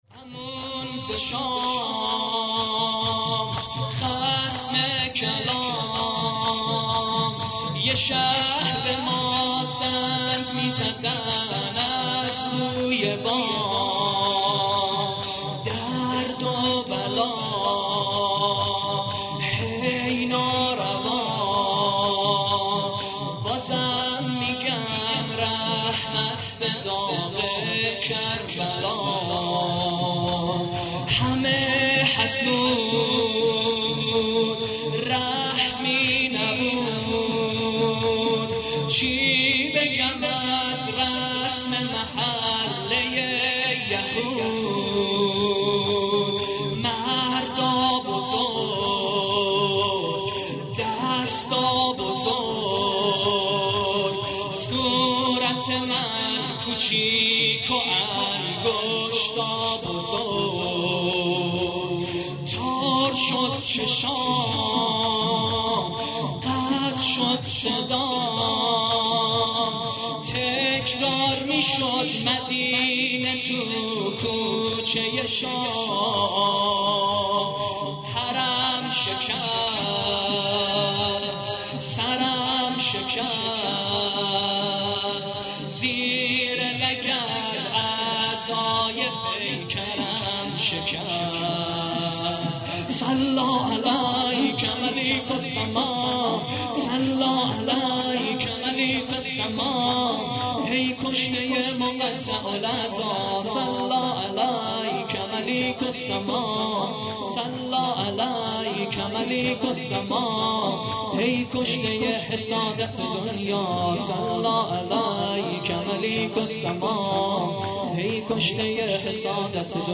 لطمه زنی ( امون ز شام